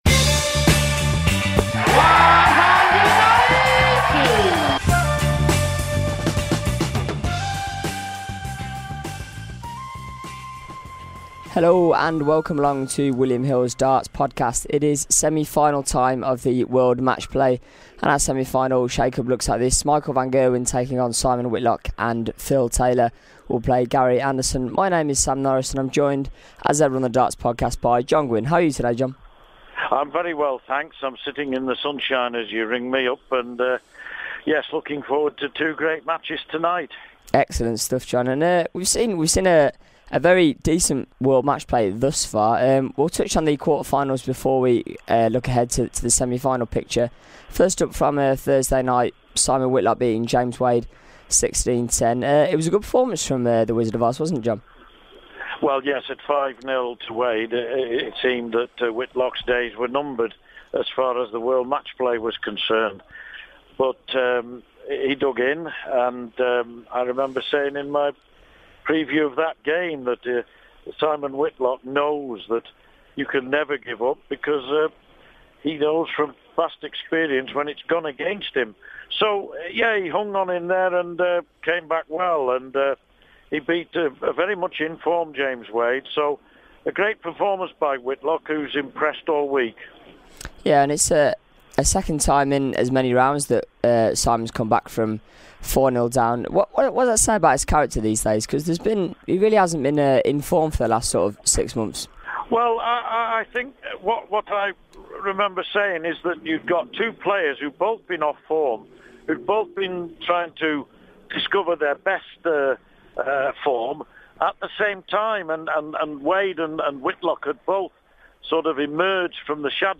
joined on the line